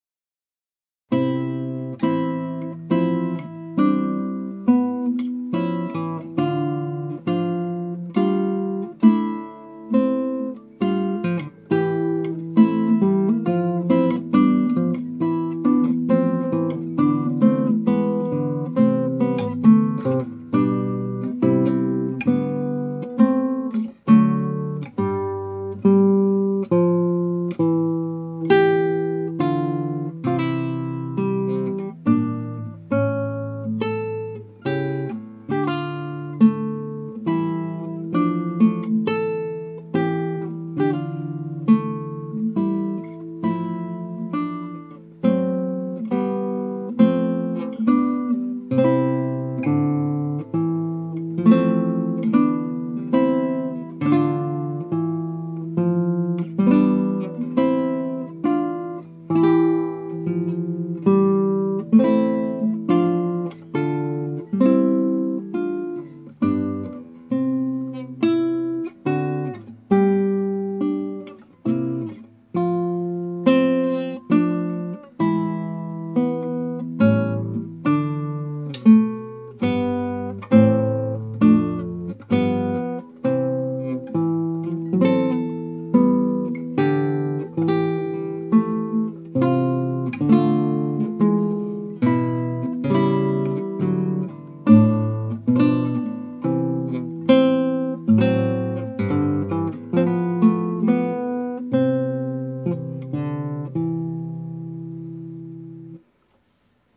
(アマチュアのクラシックギター演奏です [Guitar amatuer play] )
39小節の小さな地味な曲ですが三声のフレーズの形が次々と変化していきます。
しかし音が切れないように弾くことで精一杯でミスタッチもあちこち出てしまい練習不足な練習曲となりました。
なお、最初の演奏と録音があまりにも悪かったので休みに弾き込み少しマシになった演奏に入れ替えました。